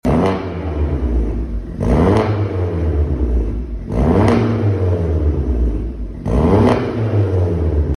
Silenziata, CAT200 Celle nel centrale,